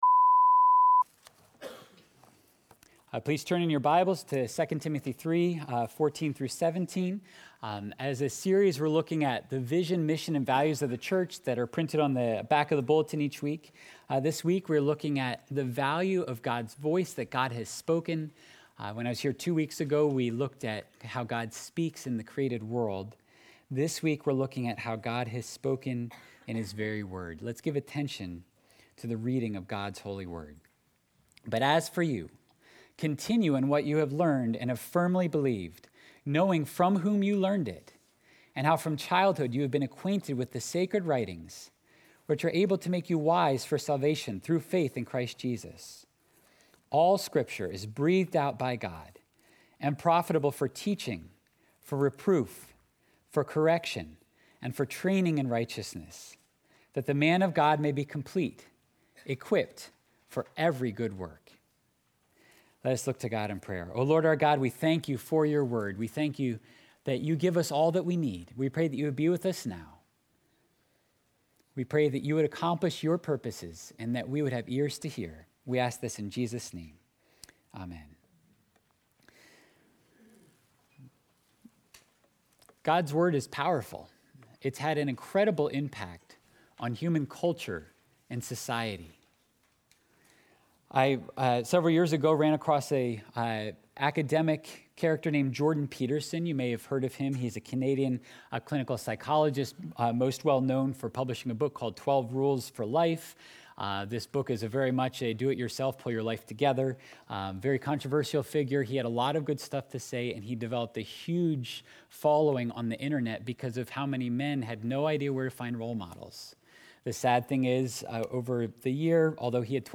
Sunday Worship – October 10, 2021 – His Voice: The Word of the Lord